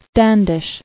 [Stan·dish]